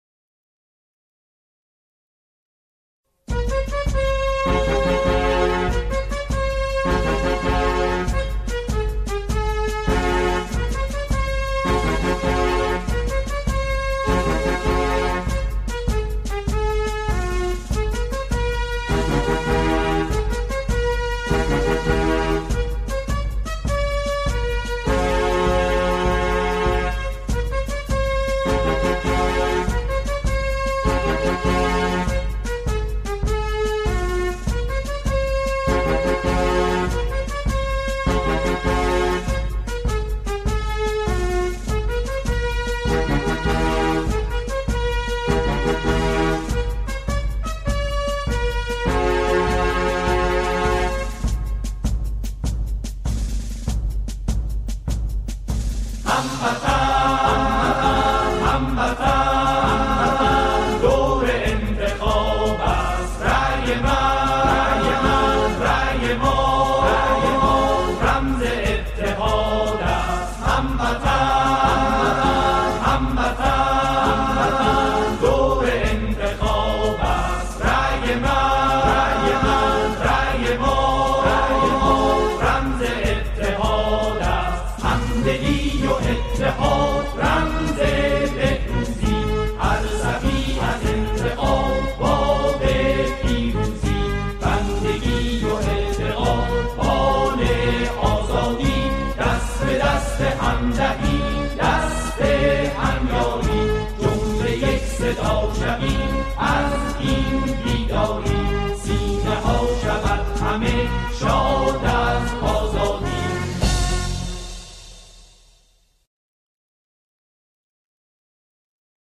همخوانی شعری درباره “انتخابات” با صدای گروهی از جمعخوانان